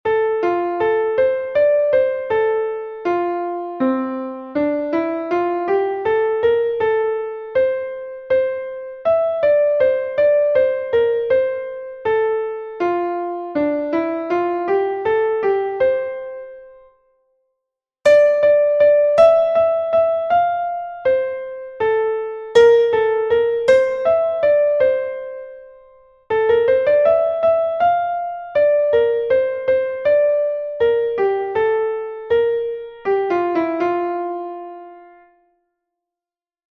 Exercise 3. The time signature change starts in ternary subdivision (6/8) and shifts to binary subdivision (3/4) every bar till bar number nine, where the time signature remains 3/4 till the end of the score.
Rhythmic reading 2